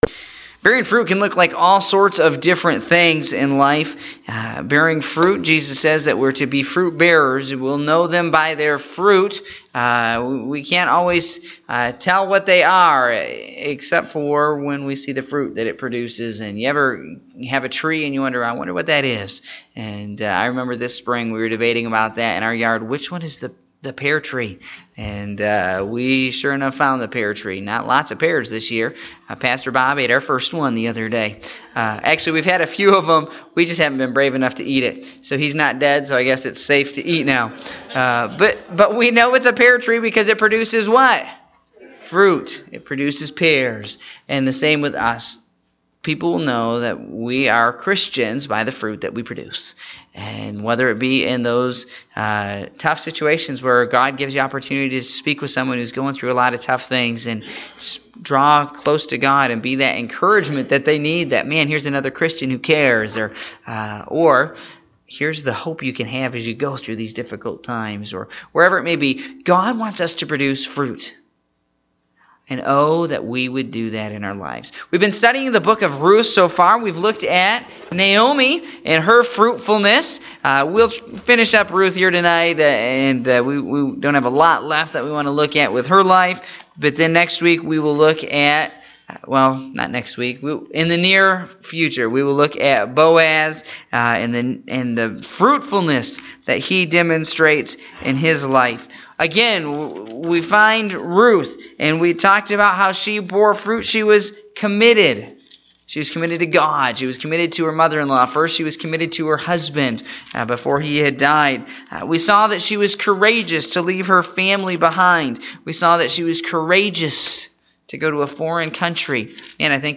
Prayer Meeting (08/02/2017) - Jeruel Baptist Church